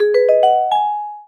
mission_success.wav